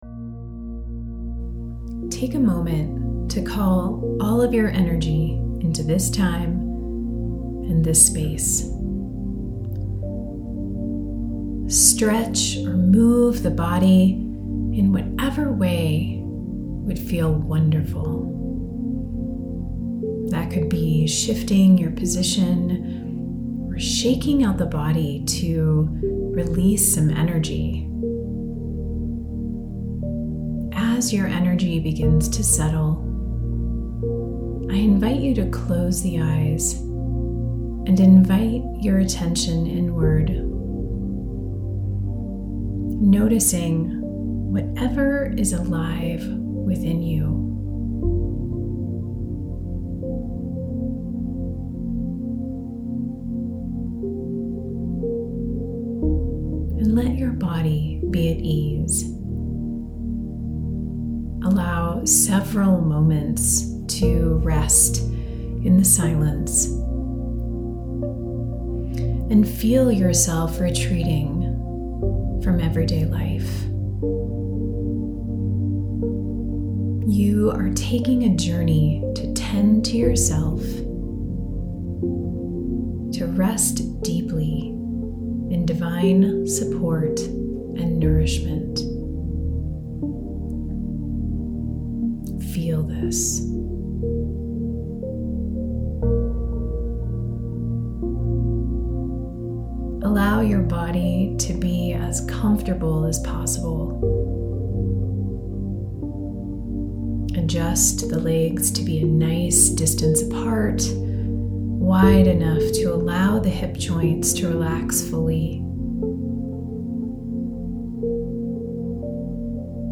Substack Yoga Nidra October 22.mp3